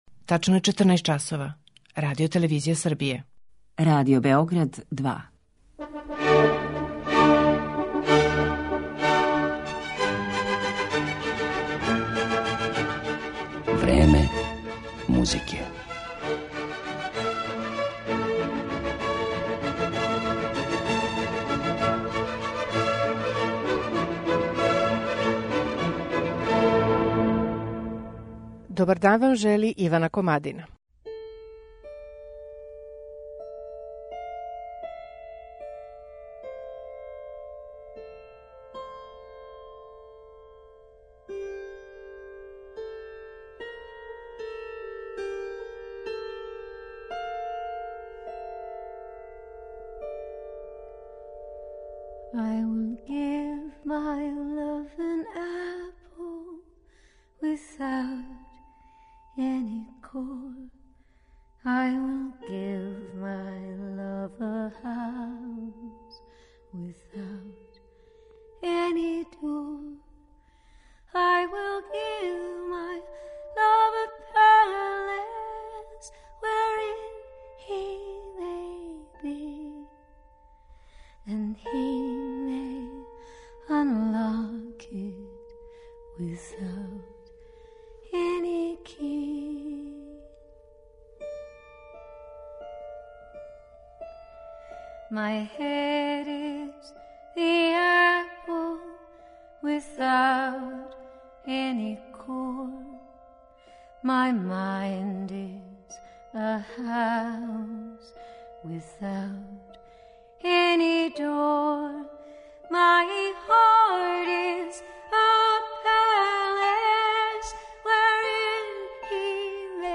Симоне Динерстин, клавир